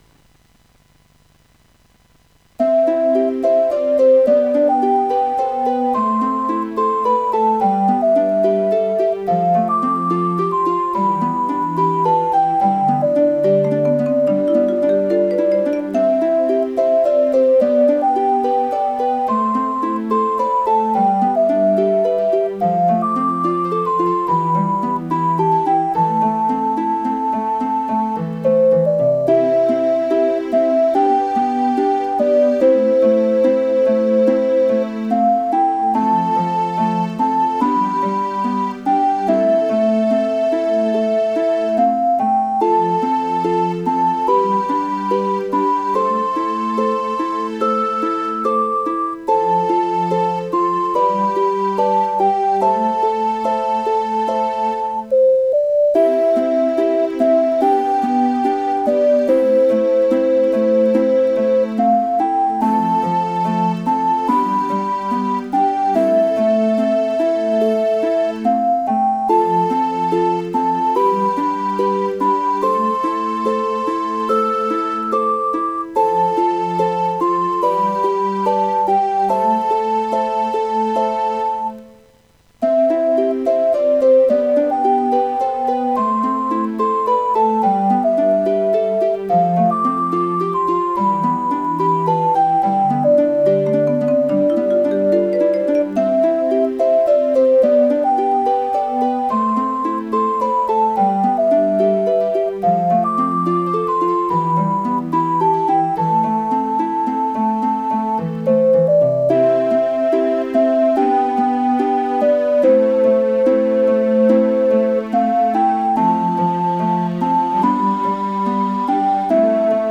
４．個人の作った曲（Desktop Music)　の紹介